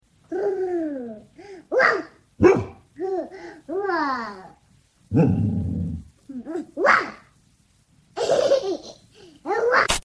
Dog and Baby
u3-Dog-and-Baby.mp3